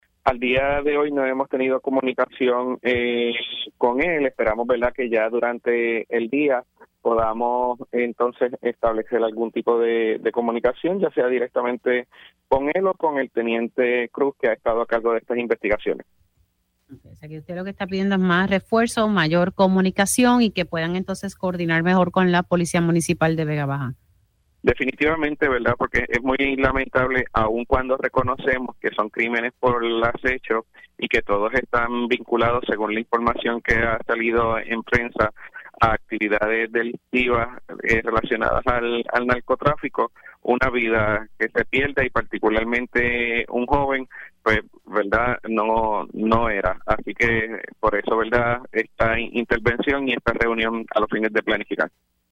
116-MARCOS-CRUZ-ALC-VEGA-BAJA-PIDE-UNA-REUNION-CON-COMISIONADO-DE-LA-POLICIA.mp3